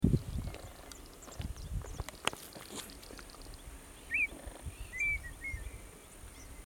Red-winged Tinamou (Rhynchotus rufescens)
Life Stage: Adult
Location or protected area: Parque Nacional El Palmar
Condition: Wild
Certainty: Recorded vocal